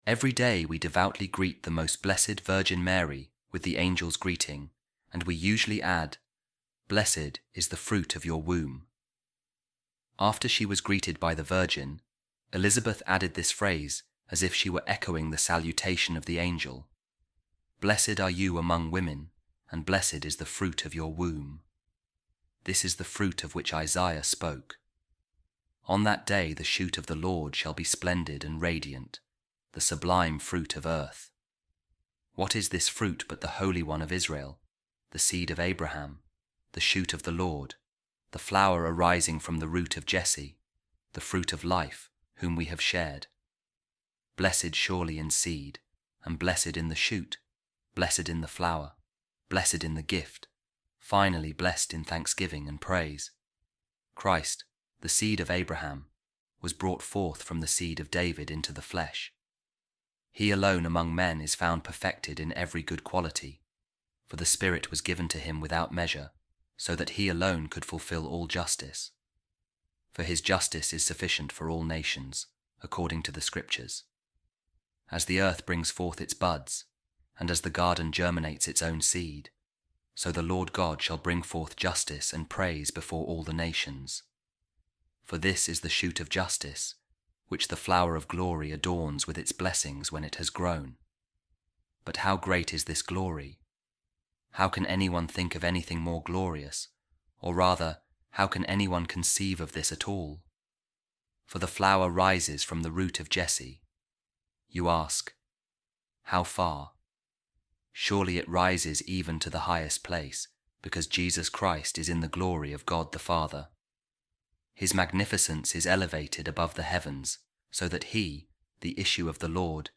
A Reading From A Sermon Of Saint Baldwin Of Canterbury, Bishop | A Flower Grew Up From The Root Of Jesse